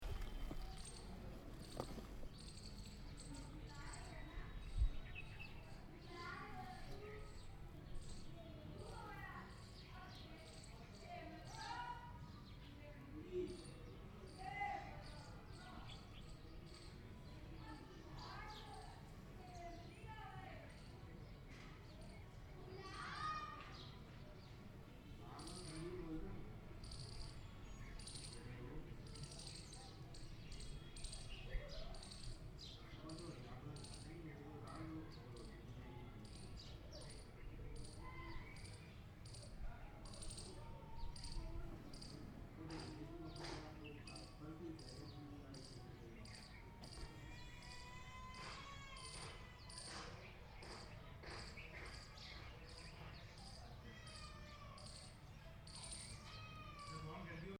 les_vaches.mp3